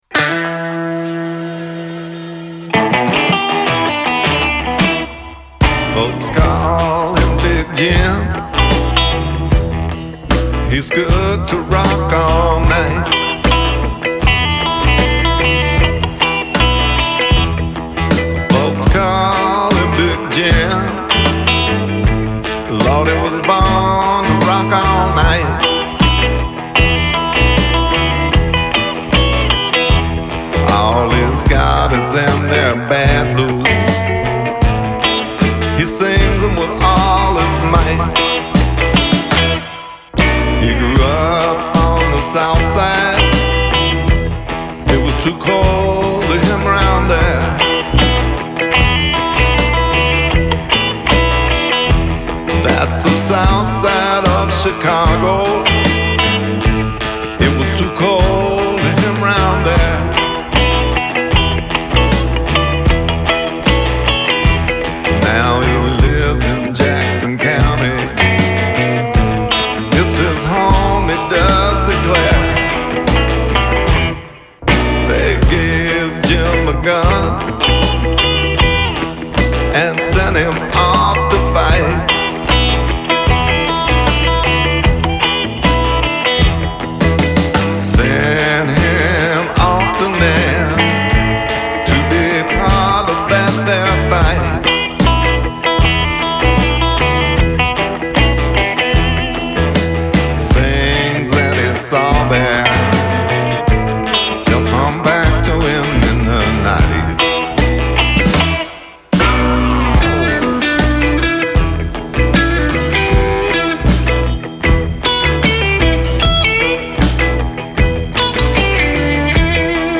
It’s blues.